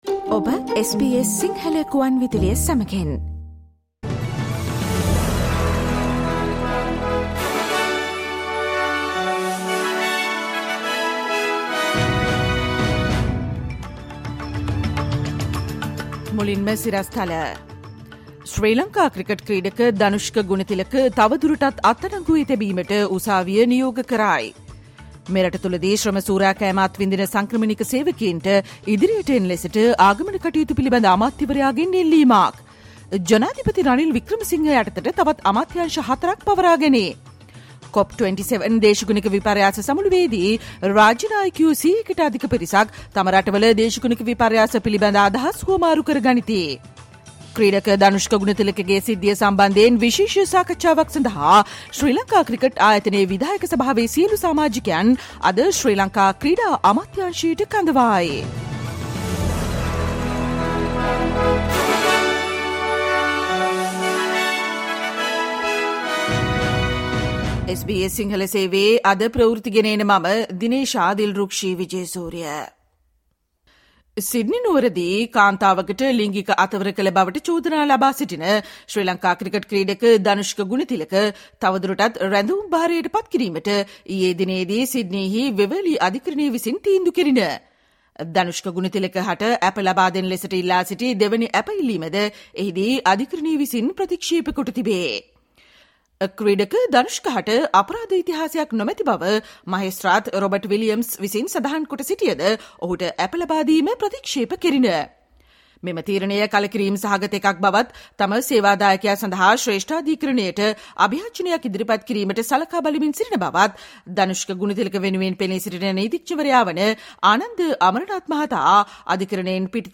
Listen to the SBS Sinhala Radio news bulletin on Tuesday 08 November 2022